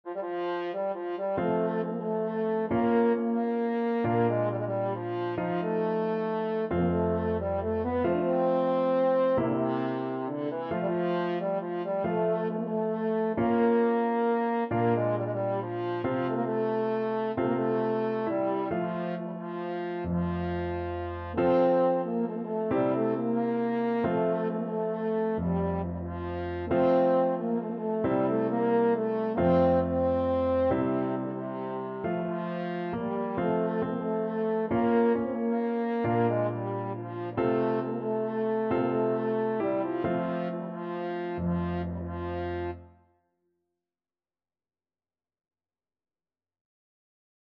French Horn
Traditional Music of unknown author.
F major (Sounding Pitch) C major (French Horn in F) (View more F major Music for French Horn )
3/4 (View more 3/4 Music)
One in a bar .=45
Romanian